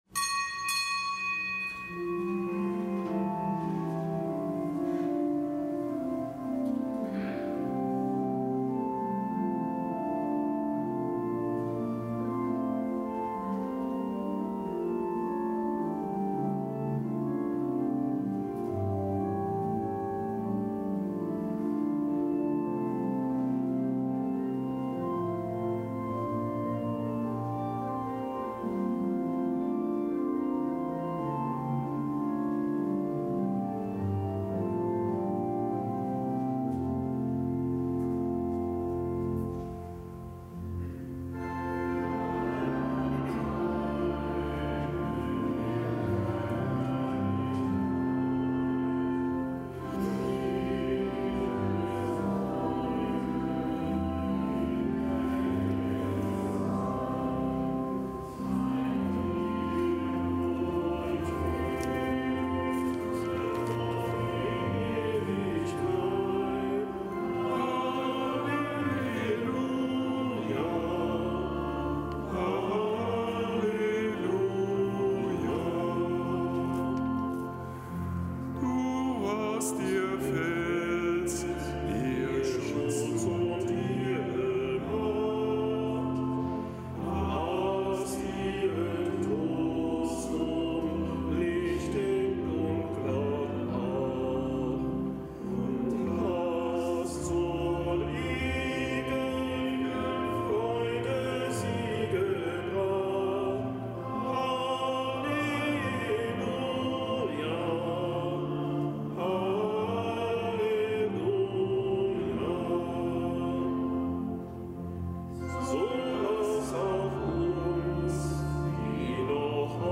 Kapitelsmesse aus dem Kölner Dom am Fest des Heiligen Petrus Kanisius, Ordenspriester, Kirchenlehrer. Zelebrant: Weihbischof Ansgar Puff.